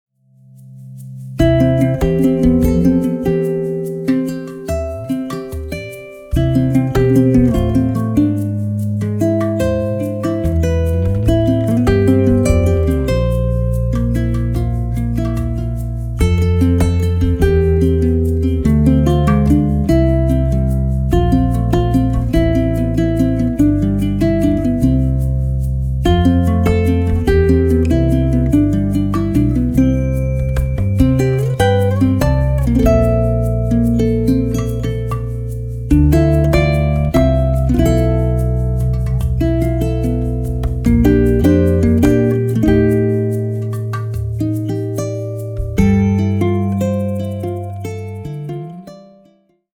original contemporary Hawaiian music
oli (Hawaiian chant)